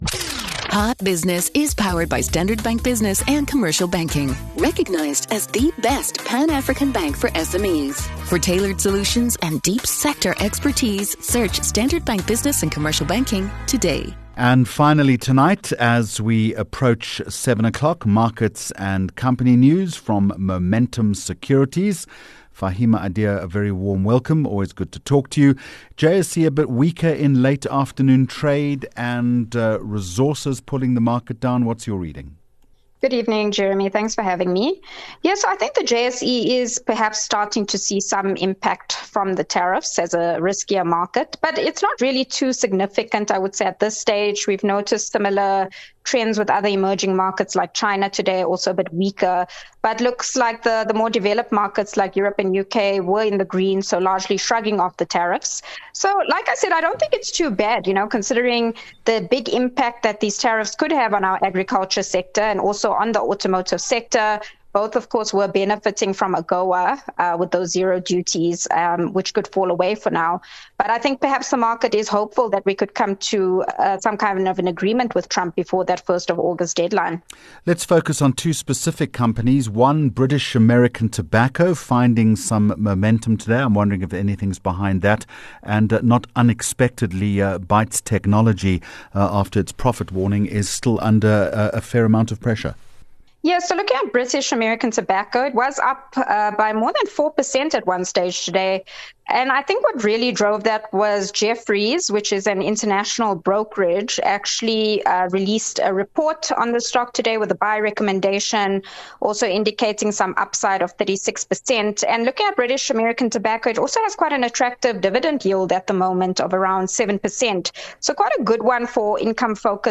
9 Jul Hot Business Interview